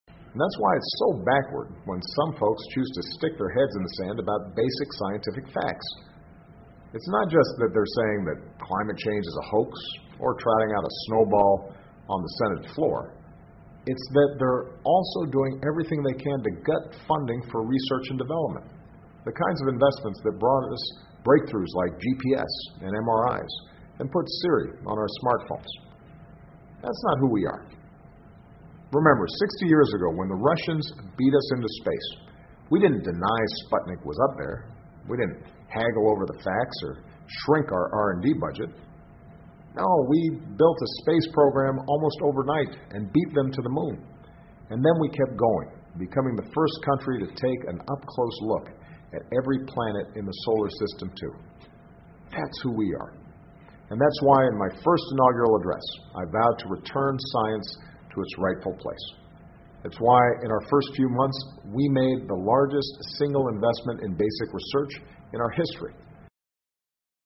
奥巴马每周电视讲话：总统呼吁确保美国引领世界开拓新边疆（02） 听力文件下载—在线英语听力室